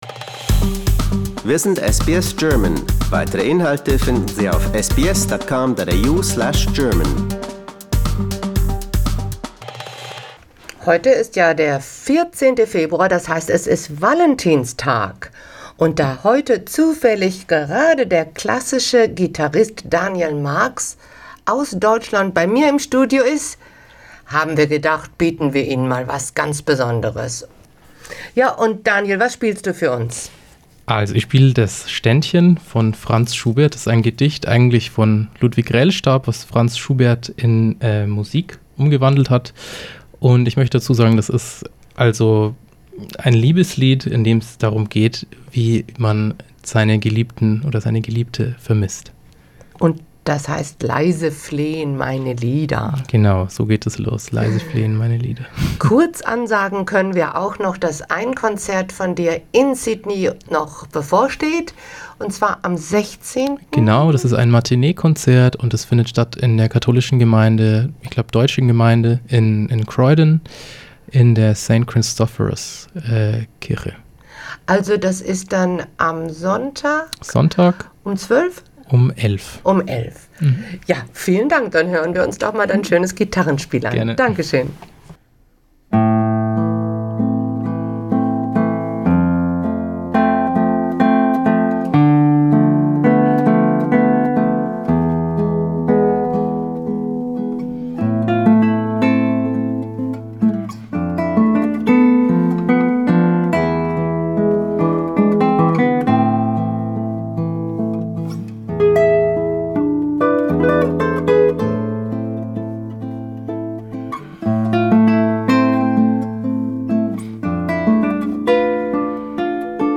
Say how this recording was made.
im SBS Studio